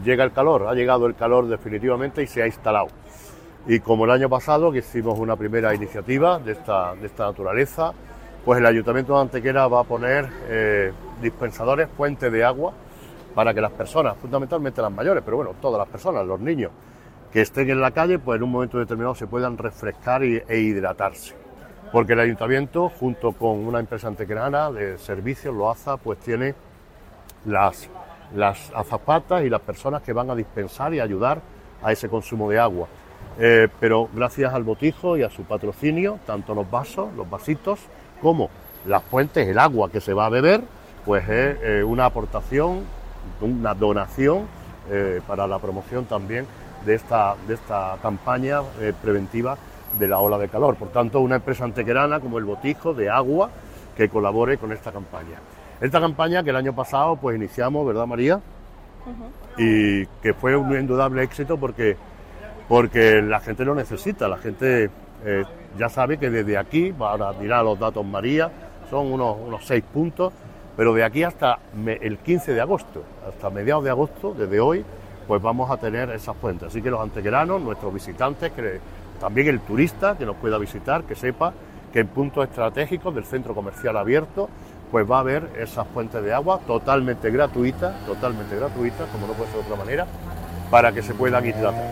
El alcalde de Antequera, Manolo Barón, el teniente de alcalde delegado de Seguridad, Antonio García Mendoza, y la concejal de Mayores, María Sierras, han confirmado en rueda de prensa que el Ayuntamiento de Antequera ha lanzado una campaña para mitigar los efectos negativos respecto a la deshidratación que provoca en las personas la actual ola de calor.
Cortes de voz